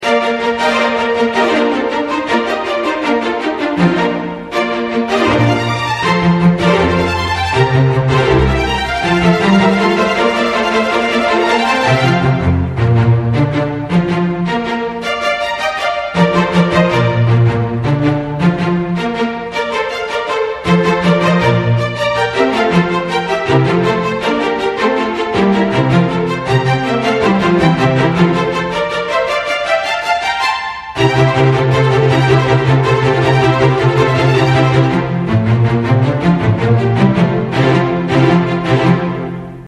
Прекрасная классическая оркестровая тема со скрипкой.
Категория: Классические рингтоны